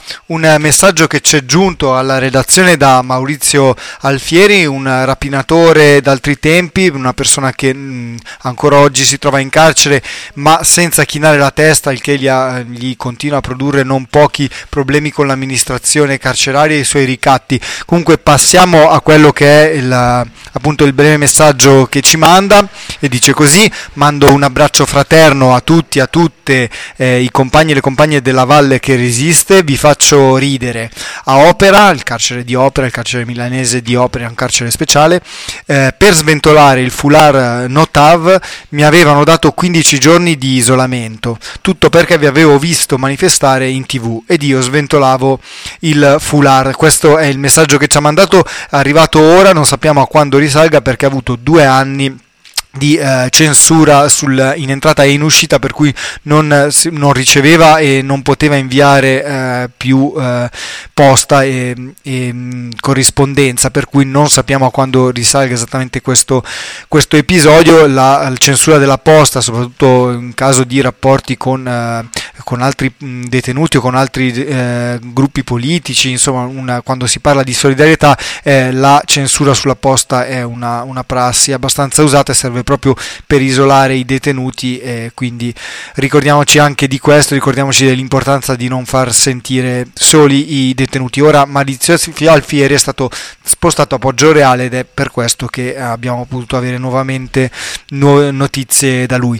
qui la lettura del suo messaggio: